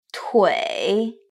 You will hear one syllable, please decide its tone.